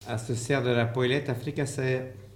Notre-Dame-de-Monts
Enquête Arexcpo en Vendée
Locutions vernaculaires